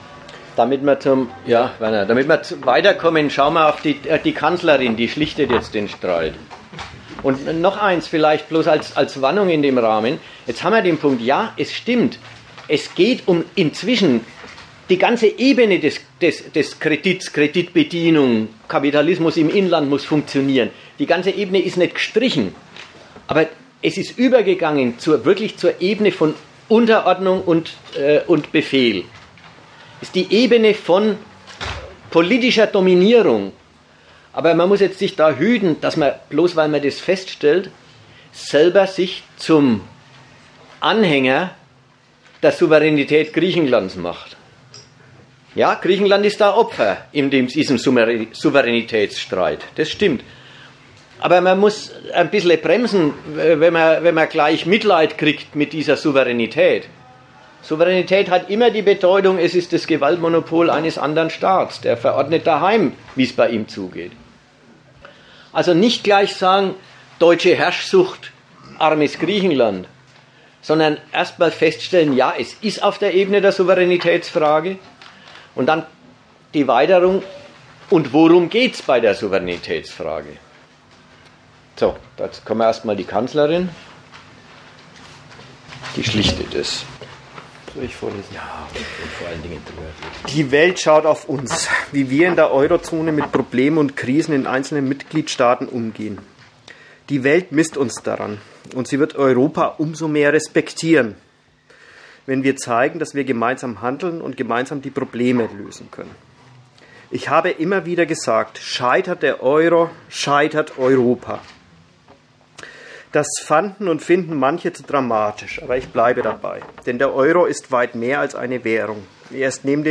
Dozent Gastreferenten der Zeitschrift GegenStandpunkt